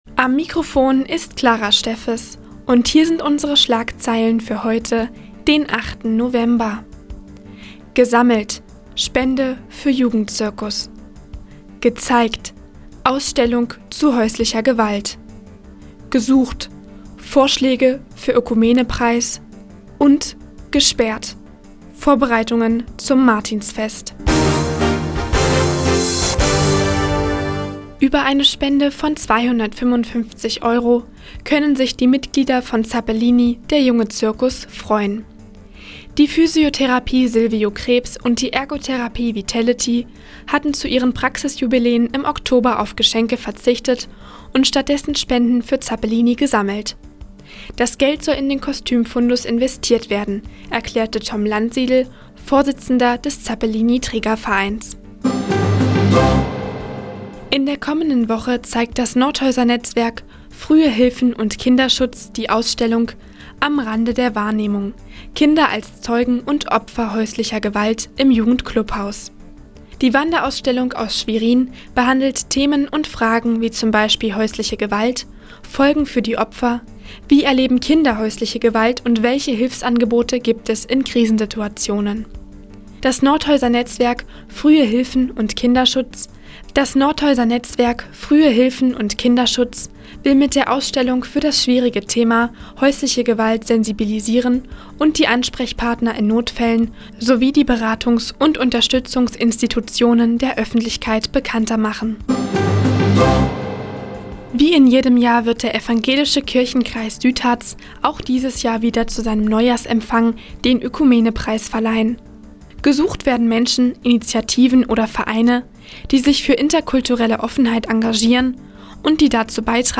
Die tägliche Nachrichtensendung des OKN ist jetzt hier zu hören.